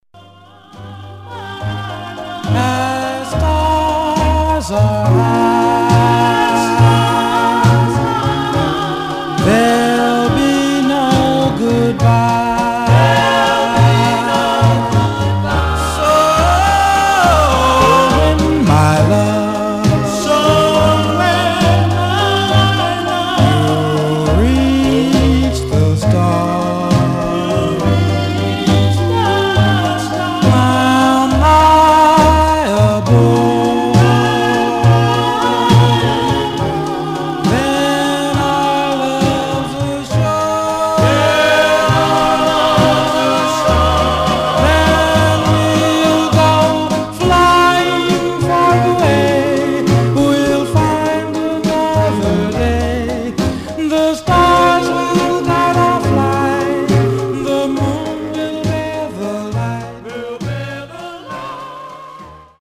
Some surface noise/wear
Mono
Male Black Group